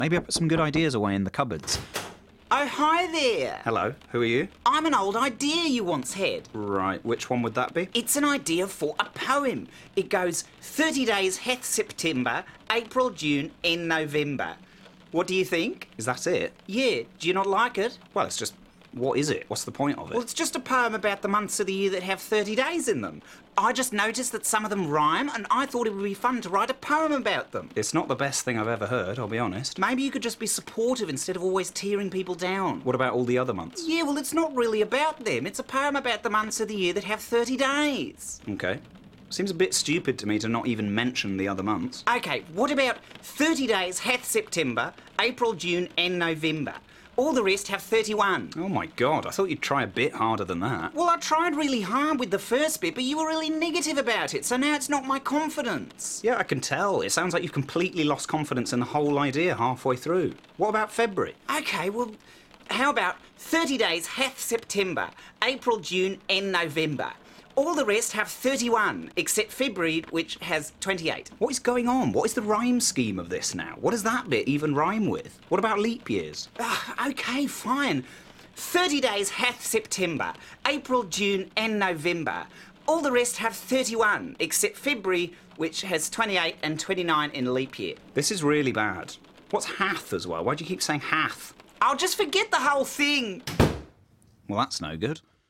Voice reels